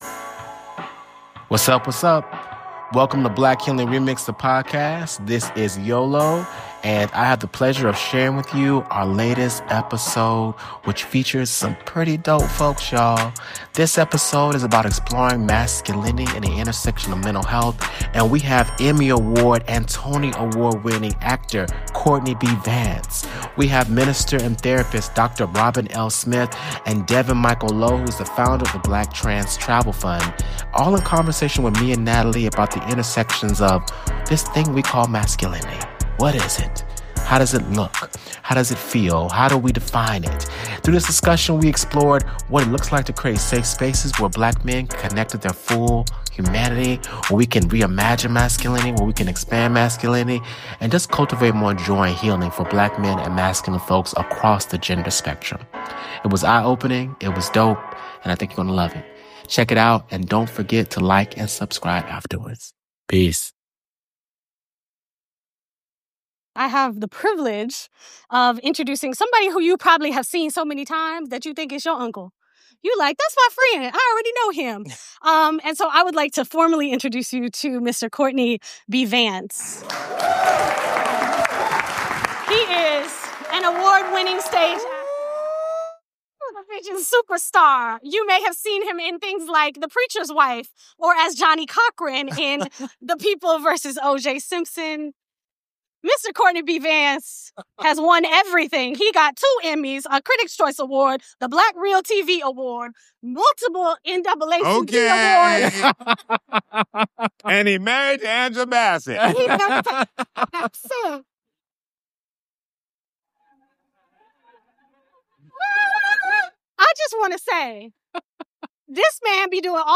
We dive deep into a powerful conversation about Black masculinity, mental health, and vulnerability. We discussed how masculinity is often defined by lies and emotional repression, le...